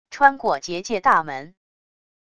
穿过结界大门wav音频